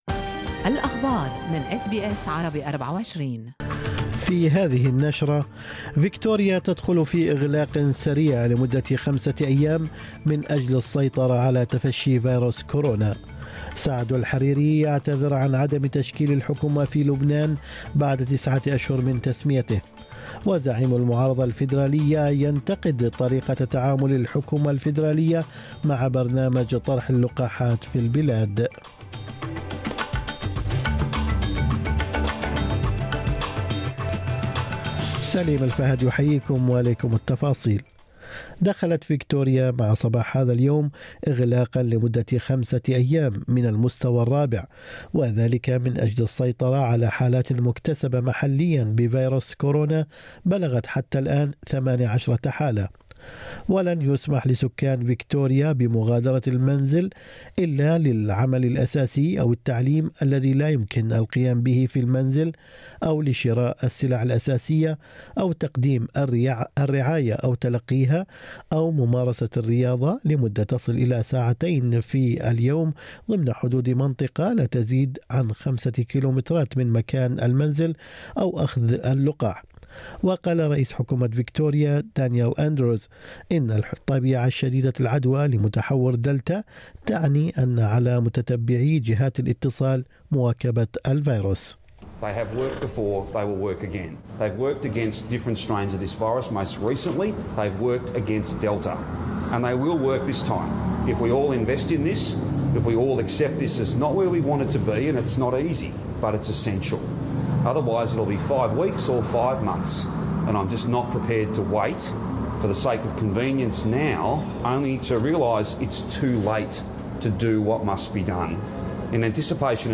نشرة أخبار الصباح 16/7/2021